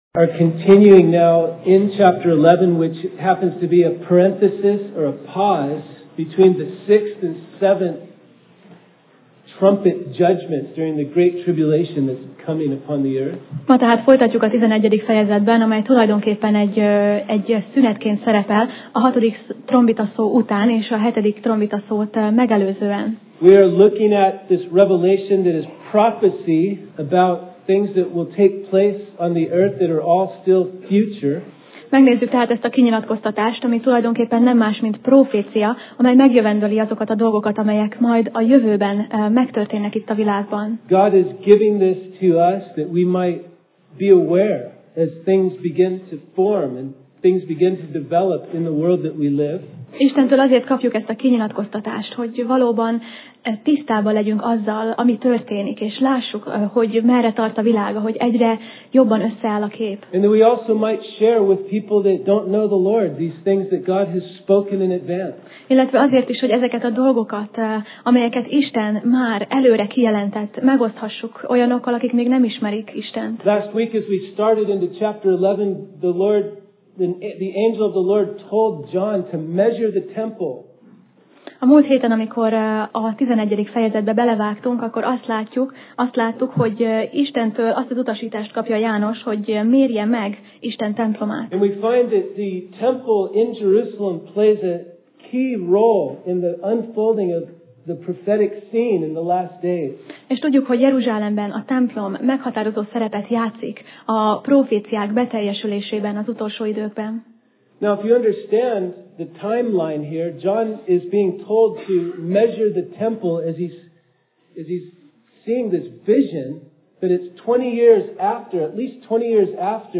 Passage: Jelenések (Revelation) 11:3-12 Alkalom: Vasárnap Reggel